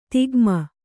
♪ tigma